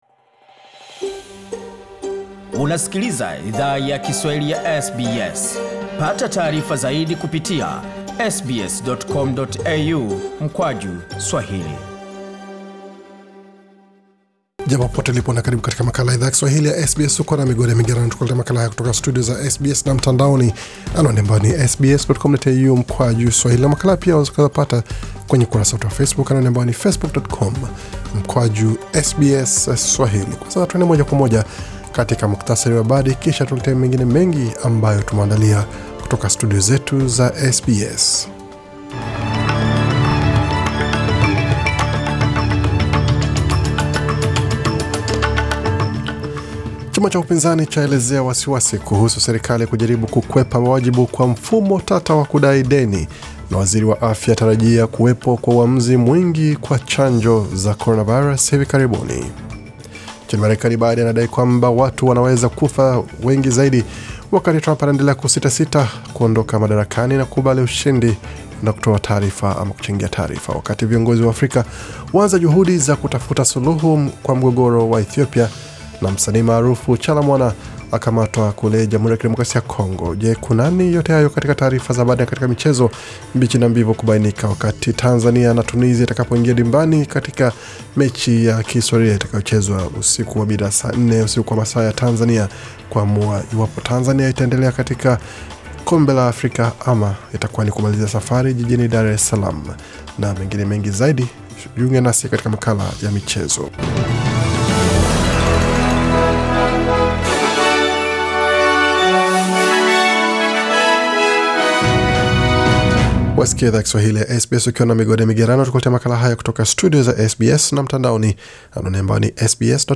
Taarifa ya habari 17 Novemba 2020